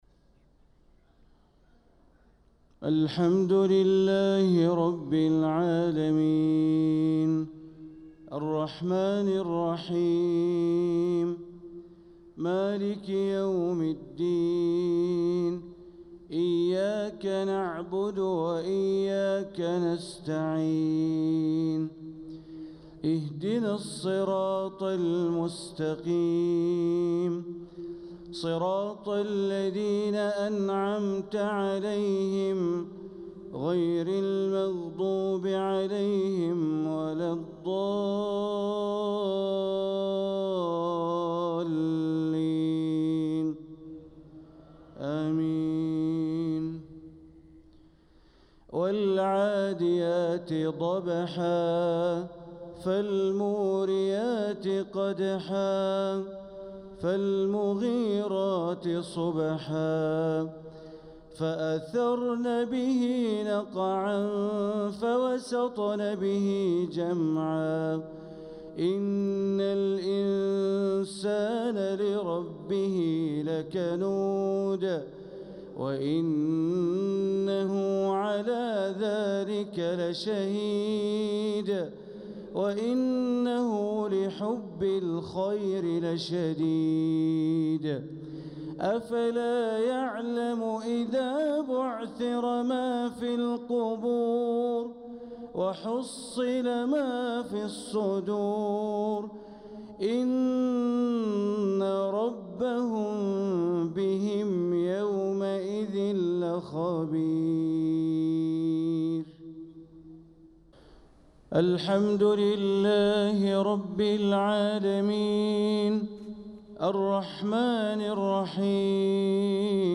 صلاة المغرب للقارئ بندر بليلة 5 صفر 1446 هـ
تِلَاوَات الْحَرَمَيْن .